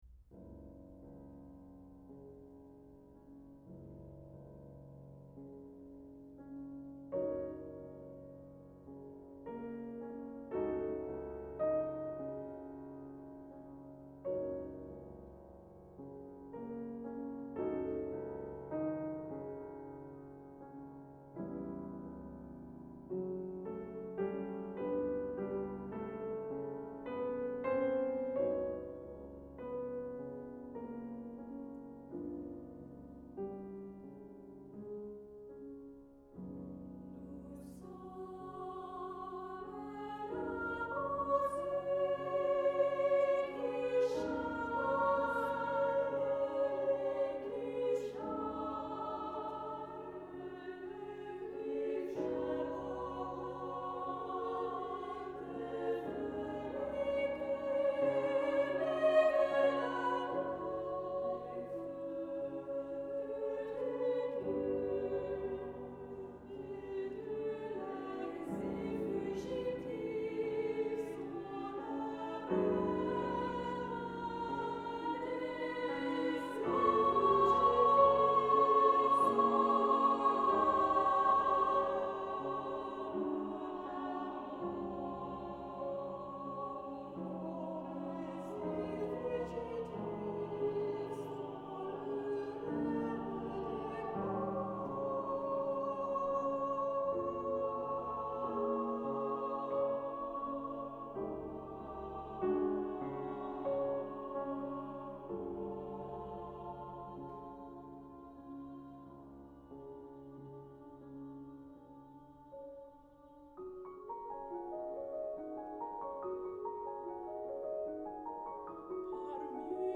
Lili Boulanger (1893–1918), sister of Nadia, composed a work for chorus and piano that strongly shows the influence of Debussy’sSirènes.” Boulanger composed Les Sirènes for soprano solo, mixed chorus (SAT), and piano in 1911, two years before she became the first woman to win the Prix de Rome.
After ten measures of piano introduction the three voice types enter canonically with the opening phrase, the soprano part having the additional indication “en dehors avec charme” [“prominently with charm”]. At the end of the first stanza an additional chorus (SAT), designated in the score as “Voix dans la coulisse (comme un murmure, au loin)” [“Voices behind the scenes (like a murmur, distant)”], sing several measures on the vowel “Ah!”
The second and third stanzas are sung by solo soprano, followed by the chorus singing the fourth. The song ends with a recapitulation of the opening stanza, once more with the offstage chorus singing “Ah” at the end as before.